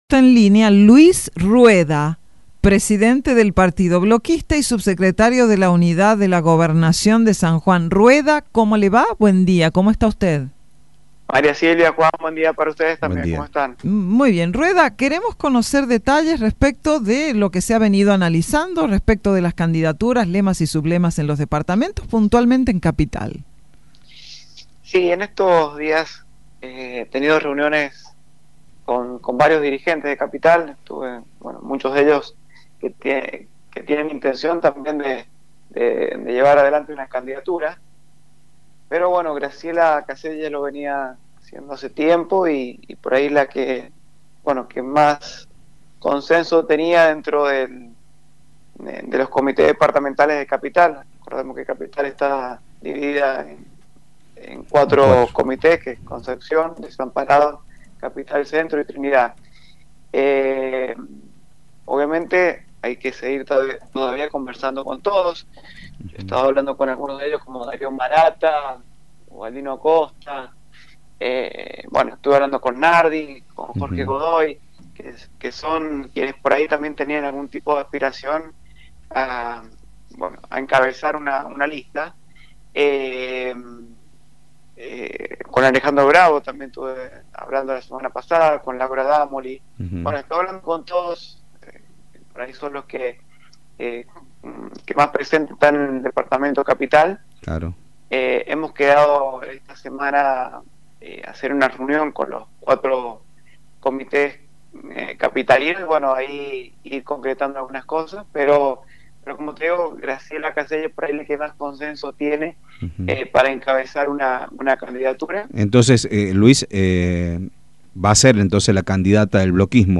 Esta mañana, Luis Rueda, presidente del Bloquismo, estuvo en los micrófonos de Radio Sarmiento para hablar acerca de las distintas candidaturas para pelear en Capital. En este marco, confirmó que la lista será encabezada por Graciela Caselles.